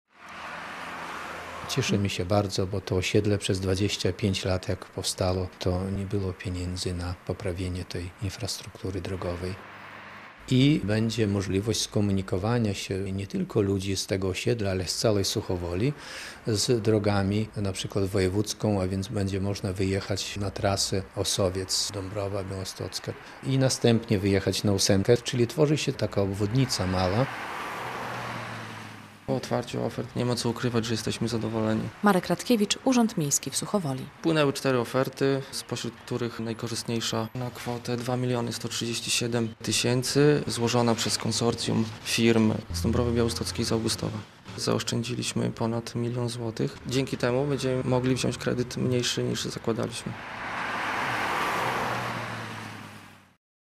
Mała obwodnica Suchowoli - relacja
Przy okazji wyasfaltowane będą wszystkie ulice przylegającego do niej osiedla - mówi burmistrz Suchowoli Kazimierz Marciszel.